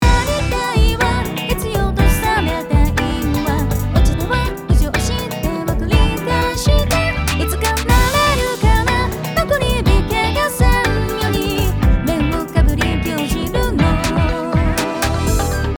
▶デモソング 完成版